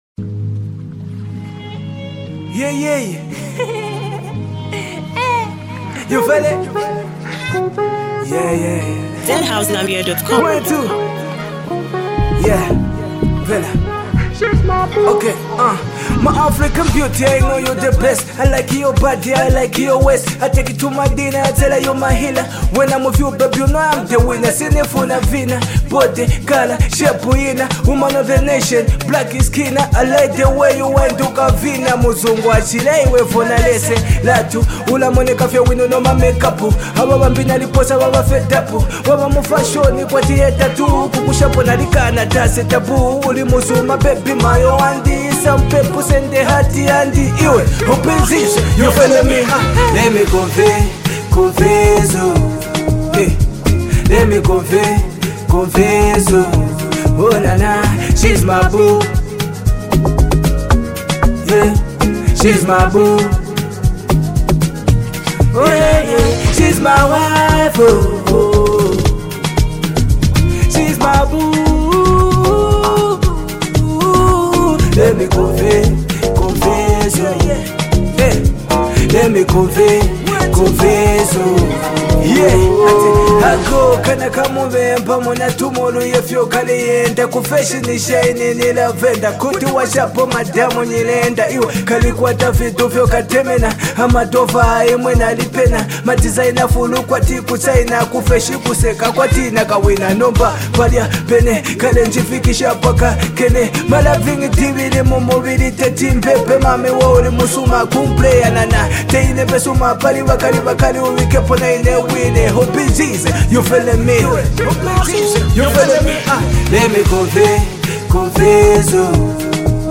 Rapper
hard-hitting bars, and top-tier production